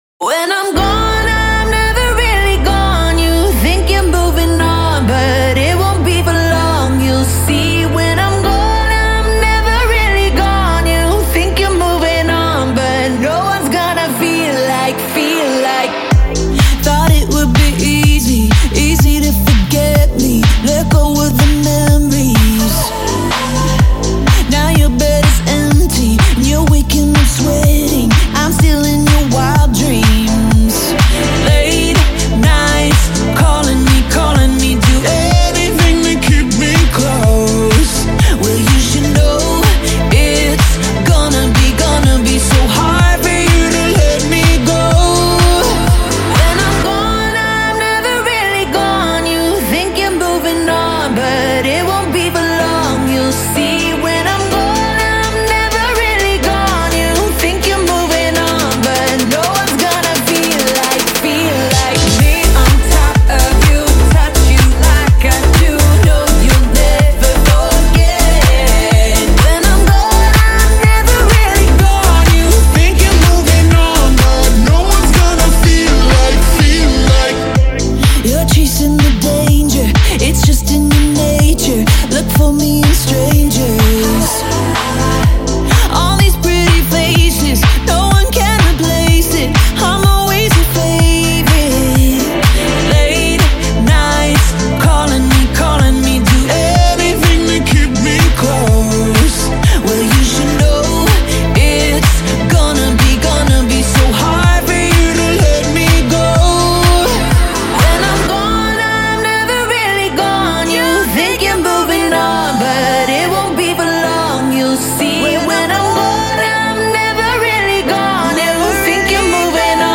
Электроника
Жанр: Жанры / Электроника